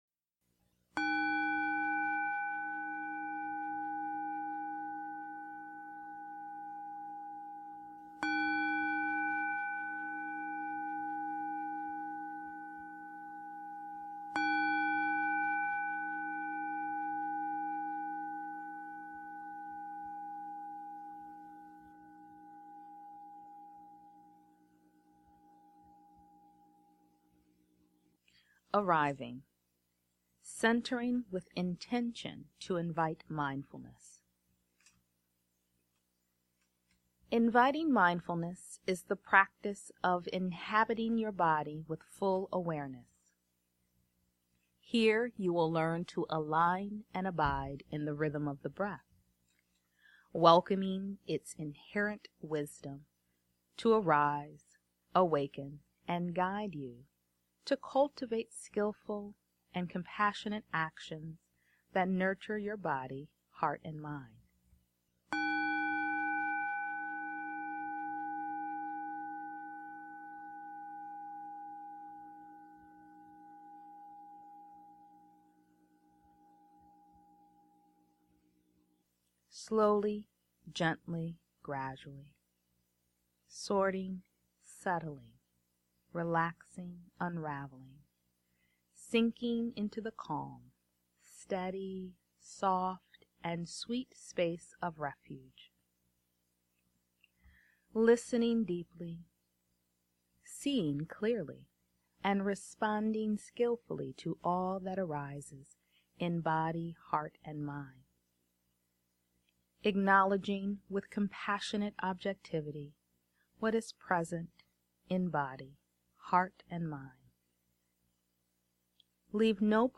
Arriving is the first of four guided meditations in my series, Embodied Self-Compassion: The Four Energies of Mindfulness.
Recorded June 2016 at Sherwood Forest Live.